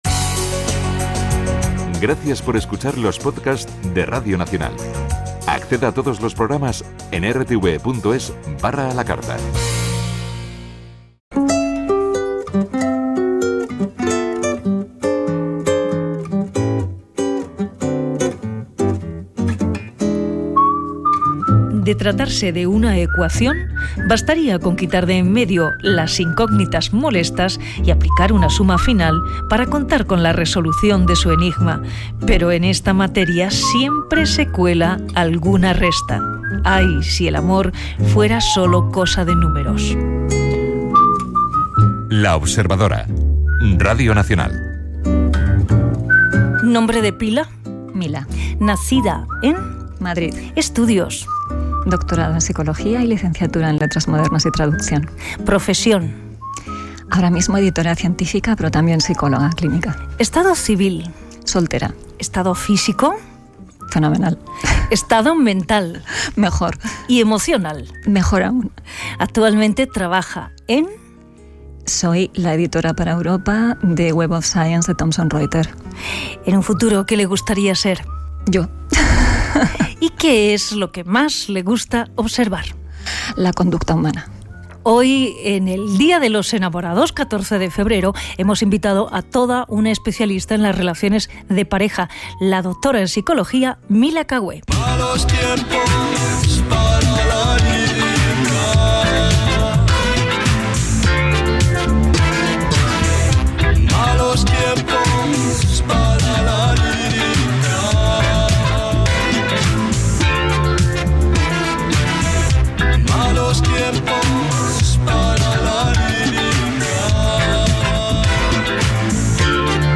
Una entrevista con Teresa Viejo tiene garantías de ser un lujo.
Teresa sabe crear ambientes íntimos, donde uno se siente como en casa…